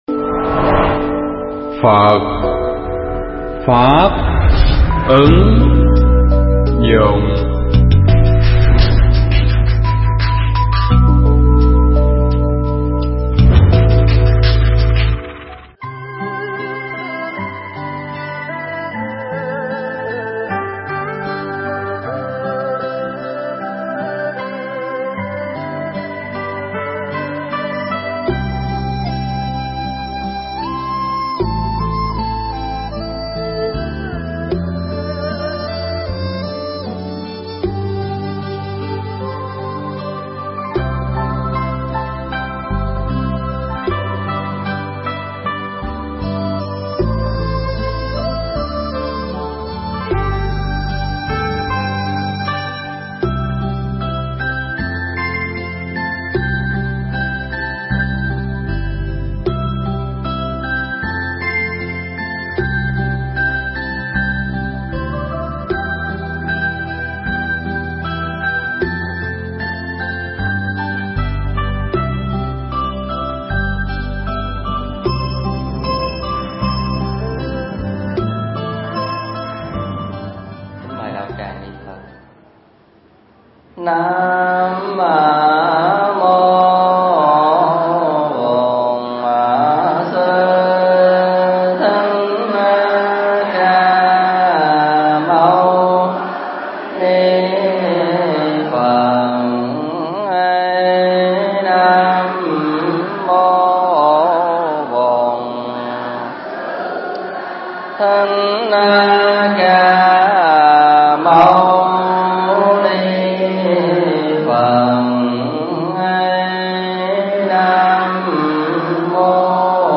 Nghe mp3 pháp thoại 5 Nguyên Tắc Đạo Đức của người Phật tử P3 – Không Tà Dâm
giảng tại: Chùa Ấn Quang (HCM)